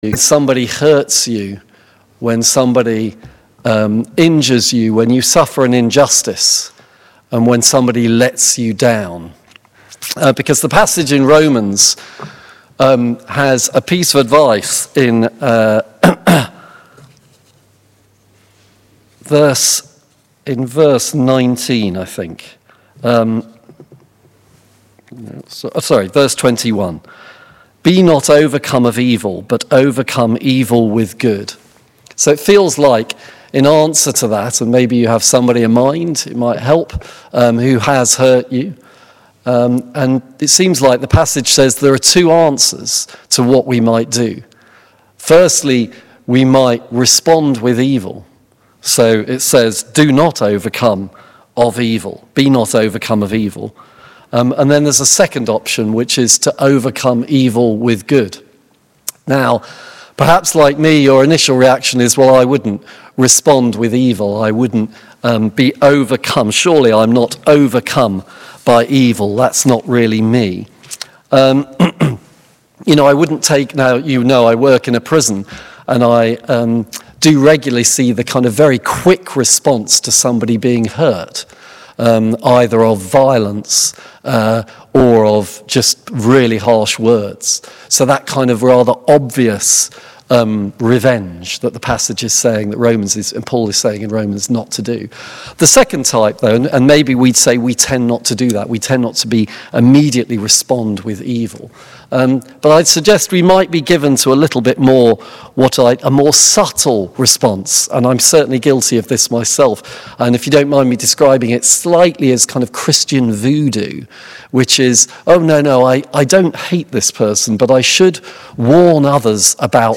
Please listen to our 8am Sermon here: